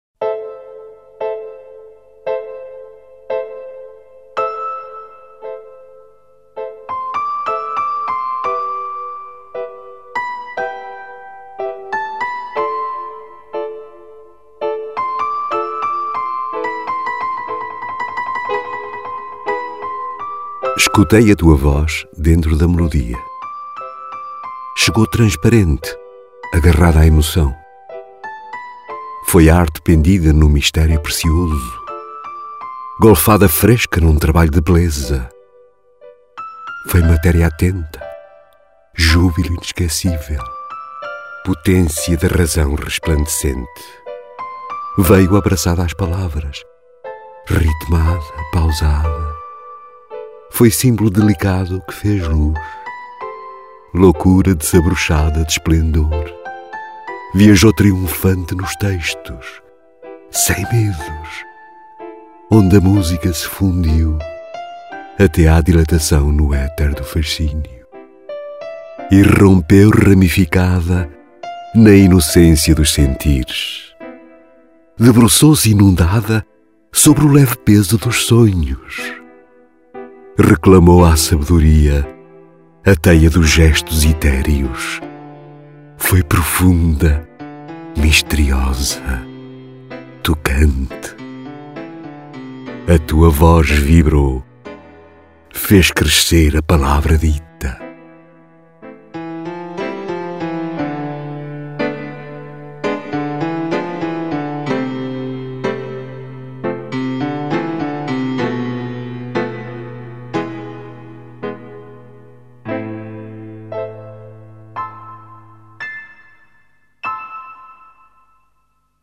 Ouvir o poema na voz do